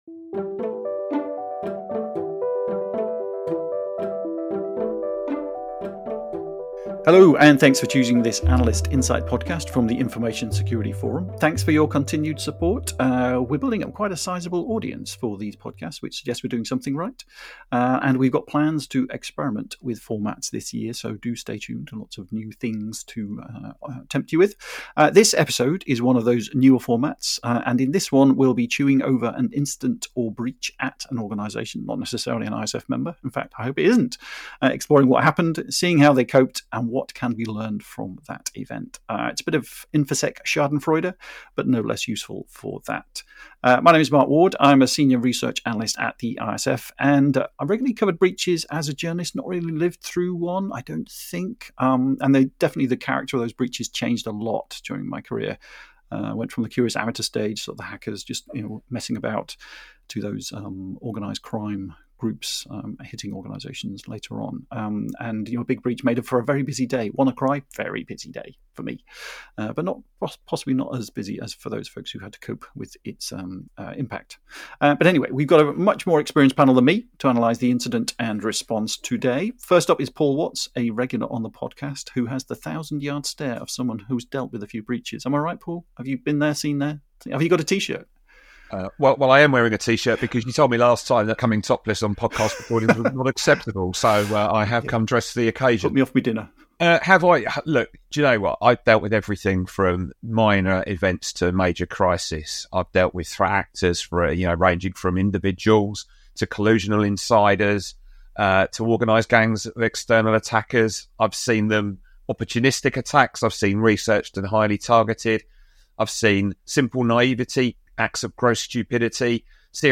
Together, they dive into a comprehensive discussion examining the report on the breach at the British Library in the UK. Listen as they aim to uncover what went wrong and share valuable insights that can be learned from the devastating incident.